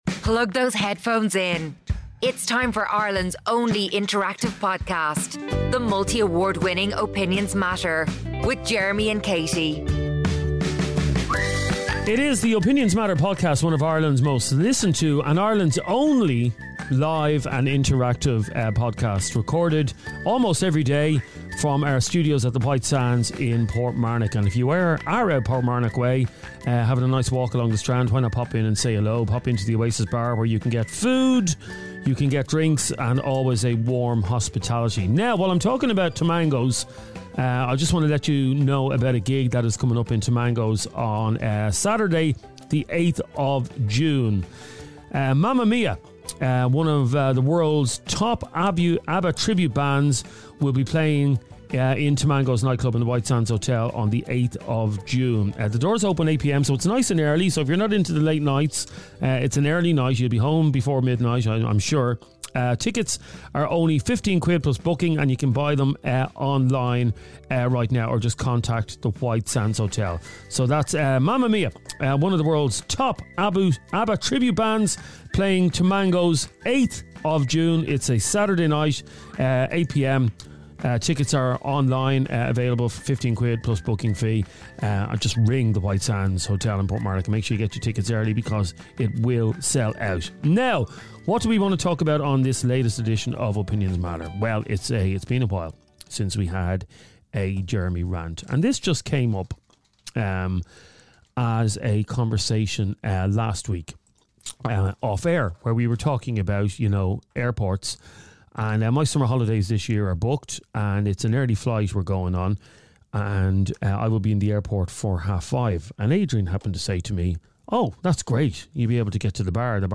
Woman brands Early Morning Airport Drinkers As "Borderline Alcoholics" Live On Air!